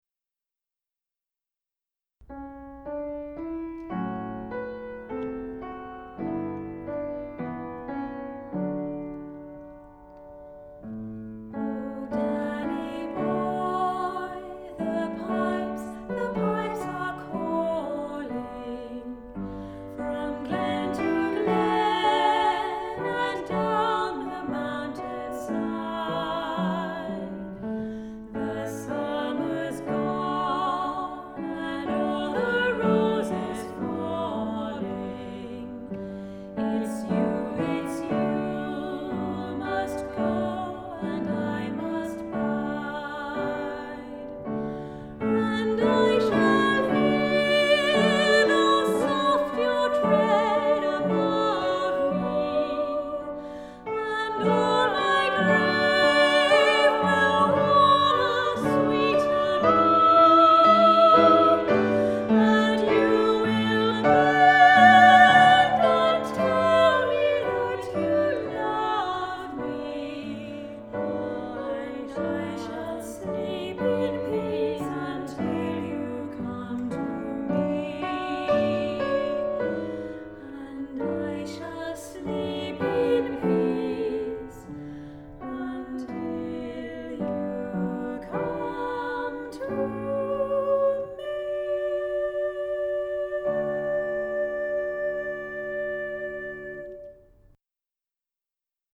Voicing: SA